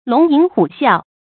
龍吟虎嘯 注音： ㄌㄨㄙˊ ㄧㄣˊ ㄏㄨˇ ㄒㄧㄠˋ 讀音讀法： 意思解釋： 吟：鳴、叫；嘯：獸類長聲吼叫。